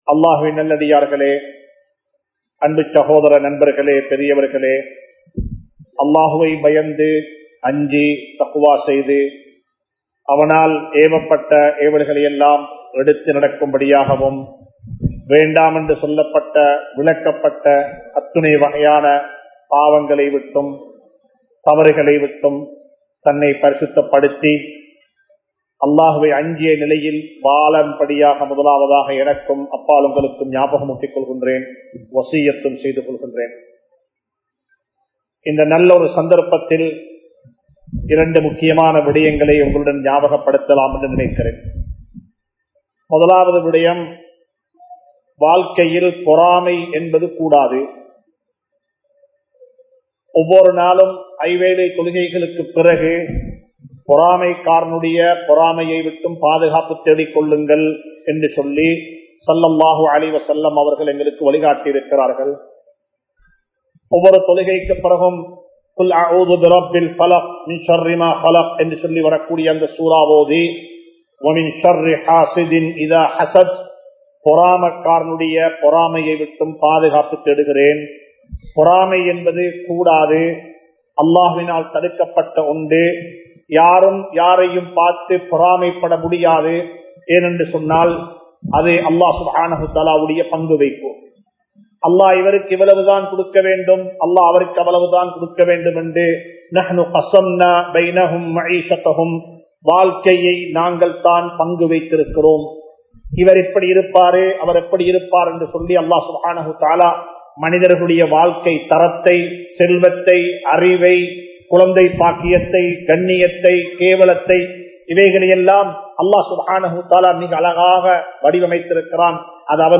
Vaalkaiyai Varalaaraha Maattrungal (வாழ்க்கையை வரலாறாக மாற்றுங்கள்) | Audio Bayans | All Ceylon Muslim Youth Community | Addalaichenai